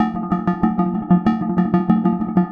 RI_ArpegiFex_95-05.wav